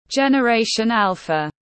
Thế hệ Gen Alpha tiếng anh gọi là generation alpha, phiên âm tiếng anh đọc là /dʒen.əˌreɪ.ʃənˈæl.fə/.
Generation alpha /dʒen.əˌreɪ.ʃənˈæl.fə/